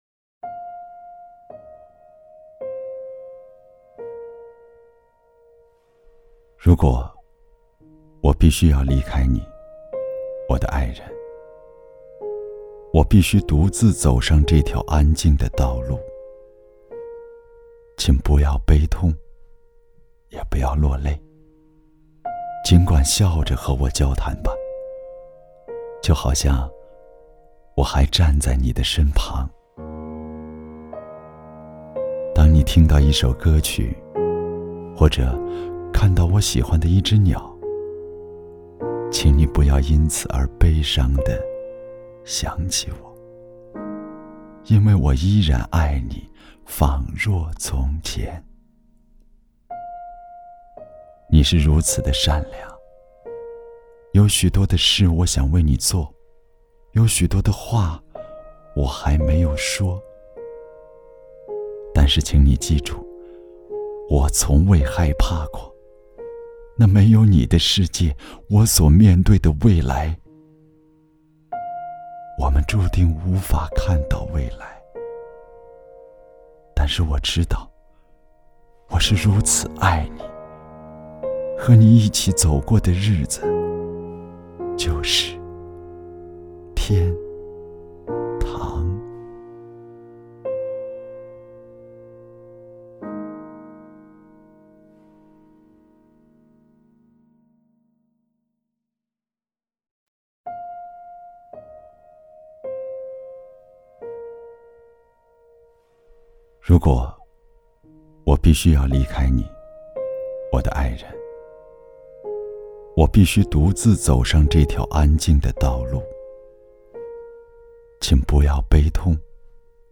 • 男1 国语 男声 【诗歌】给那些我爱的人 亲切甜美|感人煽情|素人